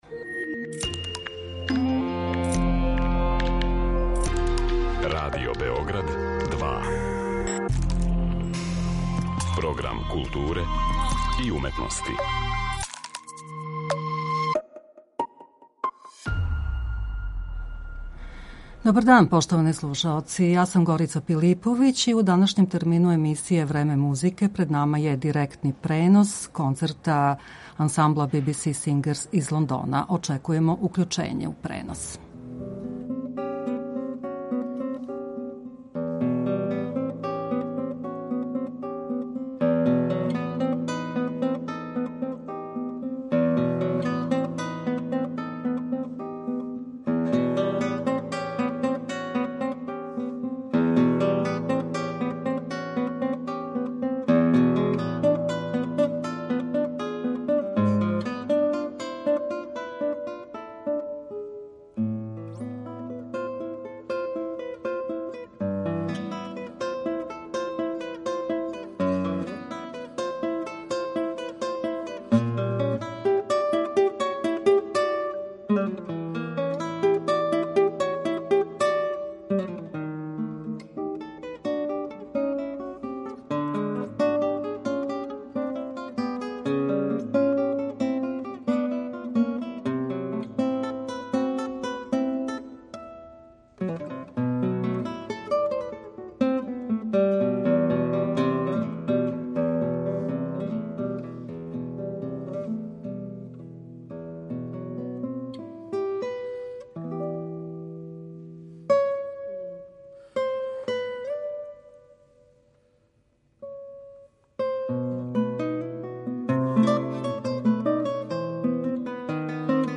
директно преносимо концерт